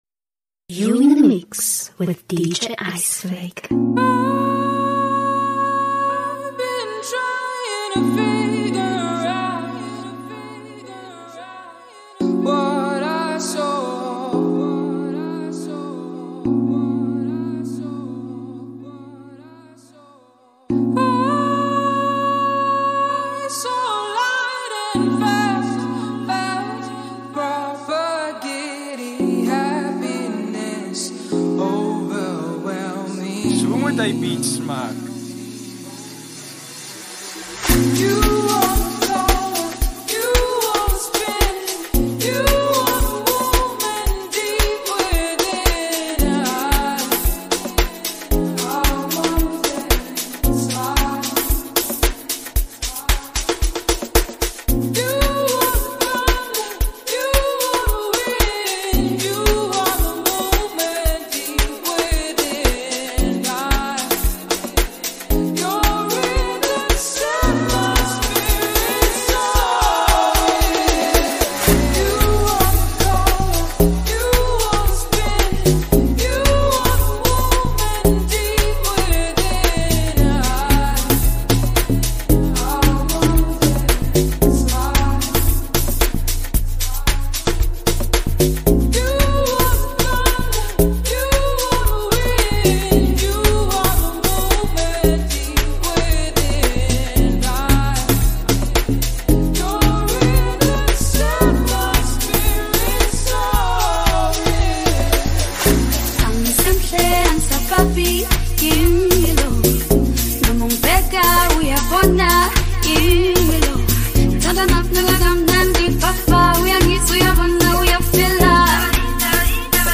mixtape